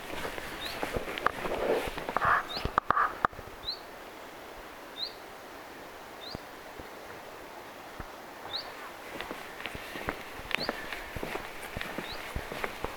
hyit-tiltaltti, 1